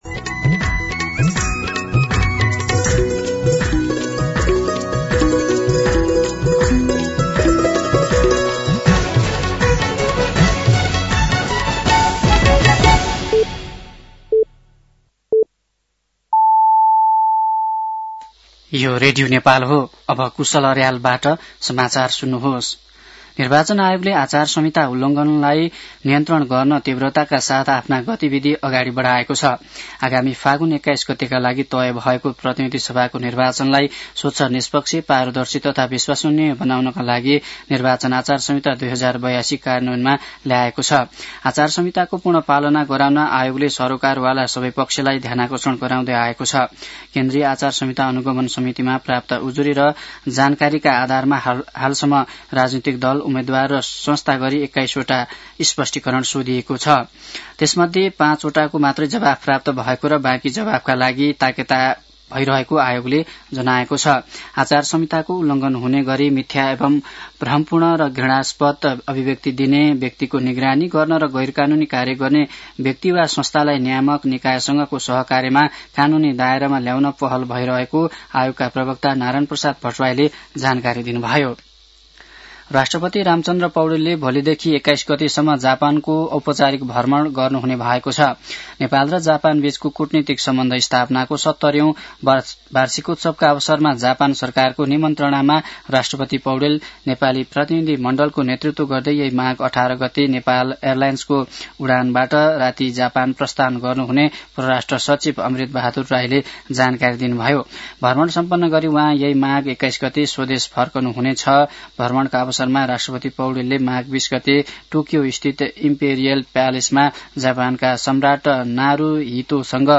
दिउँसो १ बजेको नेपाली समाचार : १७ माघ , २०८२